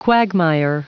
Prononciation du mot quagmire en anglais (fichier audio)
Prononciation du mot : quagmire